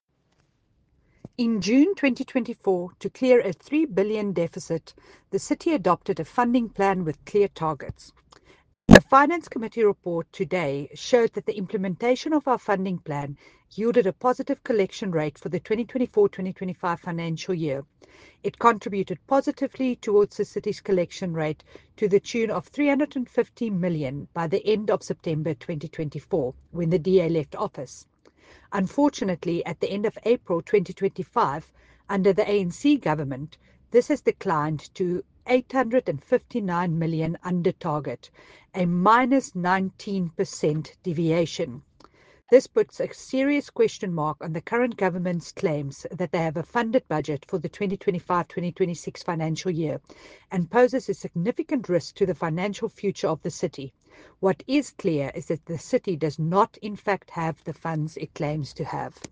Note to Editors: Please find an English soundbite by Cllr Jacqui Uys